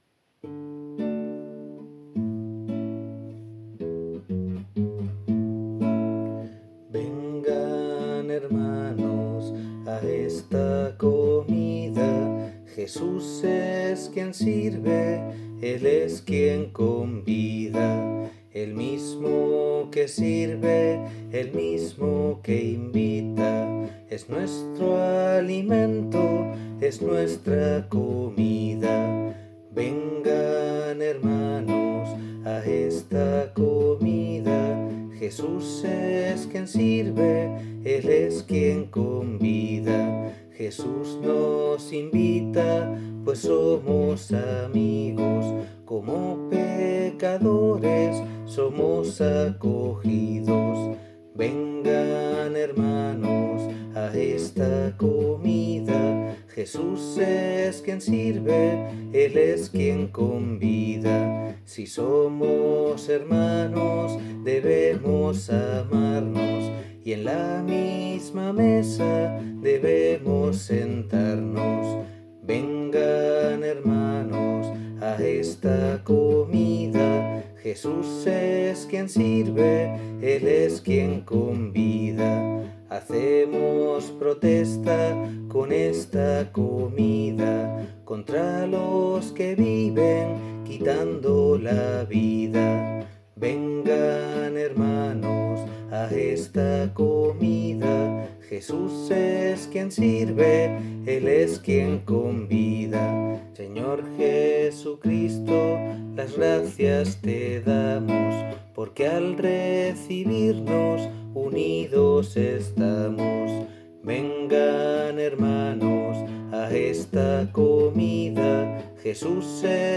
Re La Mi La